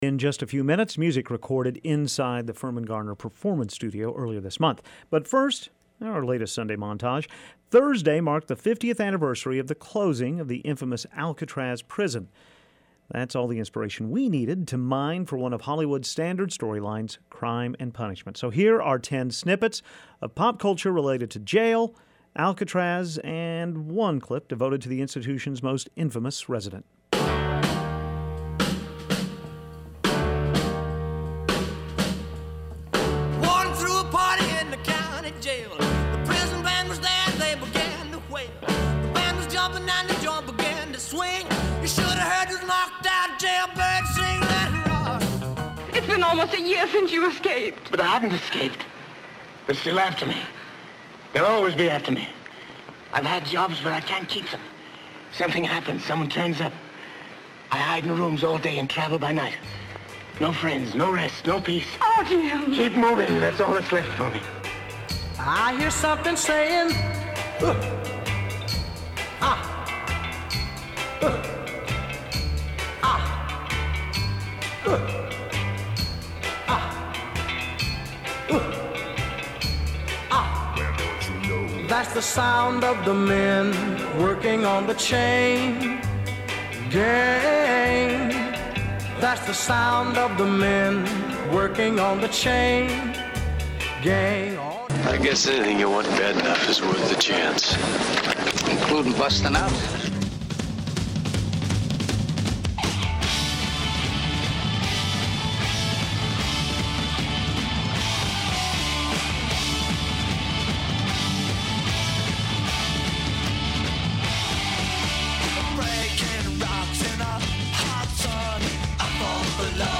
Here are the ten clips in our montage about Alcatraz this morning: 1) Elvis Presley sings Jailhouse Rock. 2) Paul Muni near the end of the 1931 gem, I Was a Fugitive From a Chain Gang. 3) Sam Cooke’s version of Chain Gang. 4) Clint Eastwood ponders his future in Escape From Alcatraz. 5) The Clash cover I Fought the Law, originally a hit by the Bobby Fuller Four. 6) Robert Redford tries to find where the bodies are buried in Brubaker. 7) Burt Lancaster welcomes back a friend in Birdman of Alcatraz. 8) The Foggy Bottom Boys’ version of In the Jailhouse Now from O Brother!